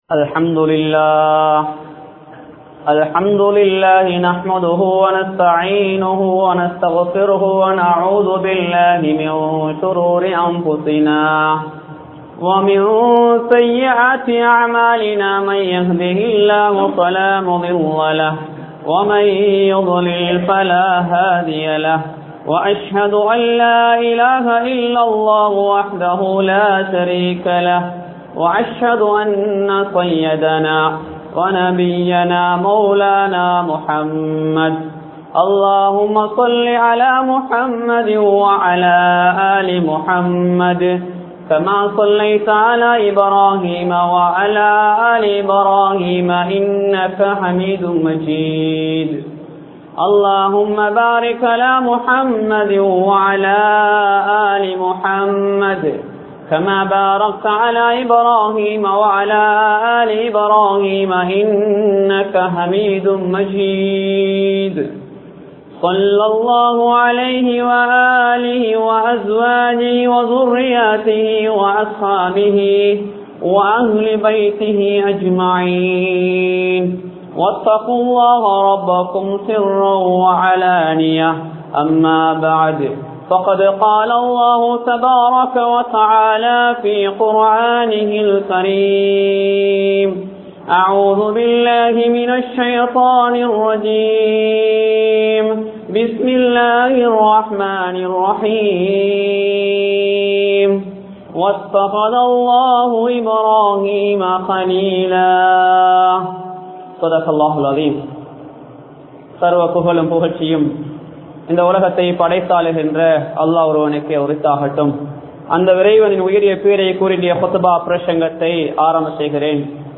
Nabimaarhalin Varalaaruhal Sollum Paadam (நபிமார்களின் வரலாறுகள் சொல்லும் பாடம்) | Audio Bayans | All Ceylon Muslim Youth Community | Addalaichenai
Kolonnawa Jumua Masjidh